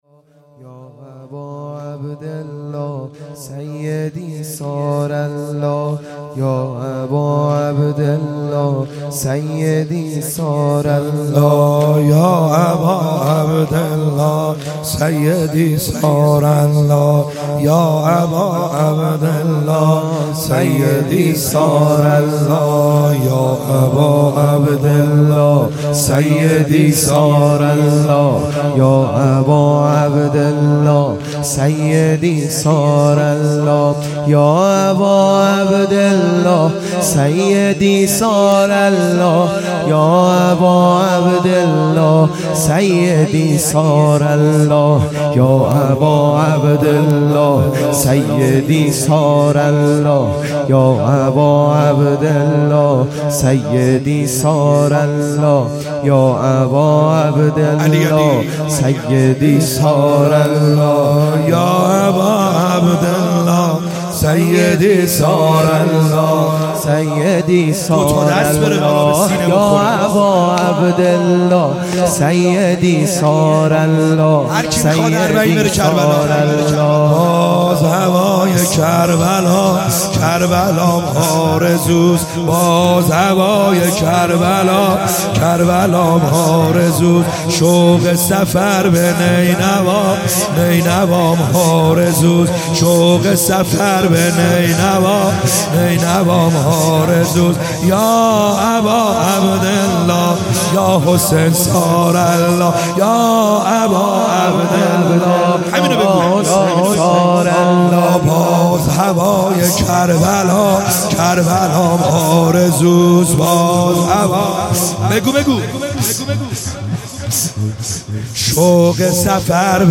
خیمه گاه - بیرق معظم محبین حضرت صاحب الزمان(عج) - زمینه | باز هوای حرمت